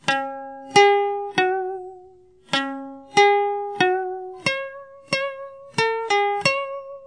Vibrato: is to make long vibrating of the lute sound and to soften the high sounds with the aim of making the sound less hard and more sentimental.
Ngón rung: là ngón tạo độ ngân dài của tiếng đàn và làm tiếng đàn mềm đi ở những âm cao, âm thanh đỡ khô khan, tình cảm hơn.